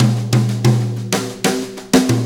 Brushes Fill 69-12.wav